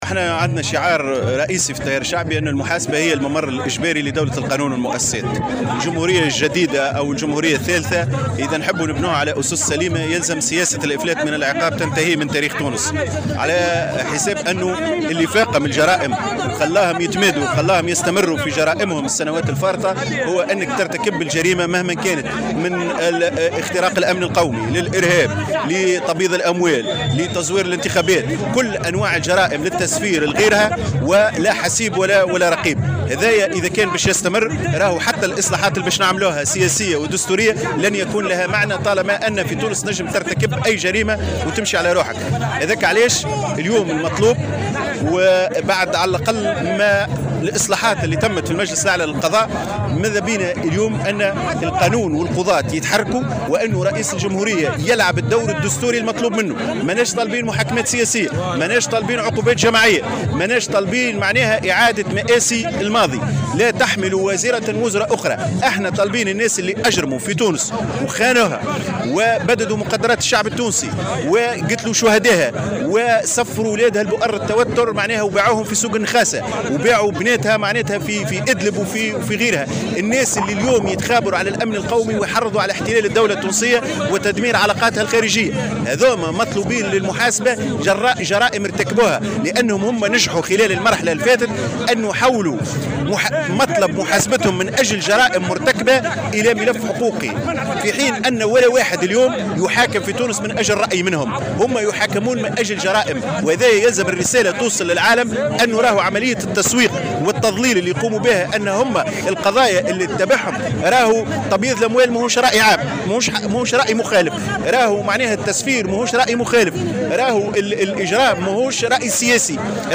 في مسيرة بالعاصمة تطالب بمحاسبة المتورطين في الاغتيالات السياسية وفي قضايا الفساد